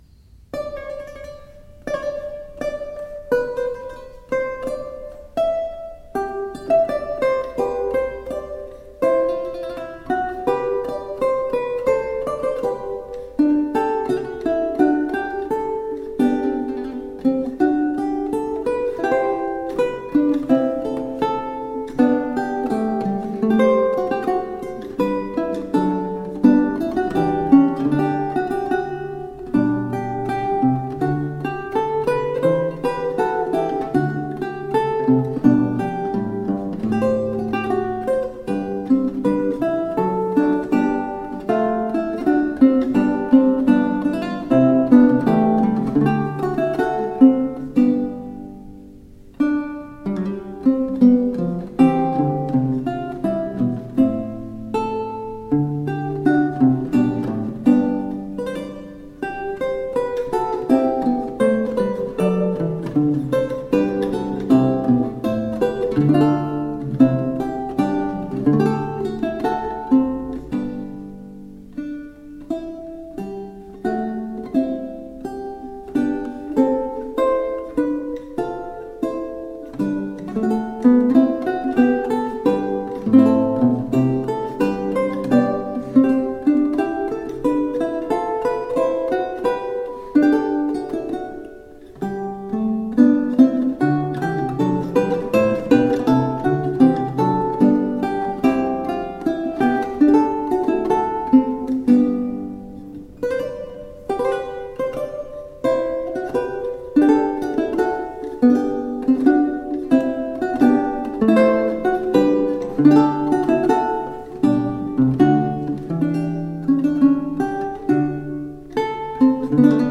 Renaissance lute.
lute
Classical, Renaissance, Instrumental